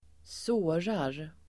Ladda ner uttalet
Uttal: [²s'å:rar]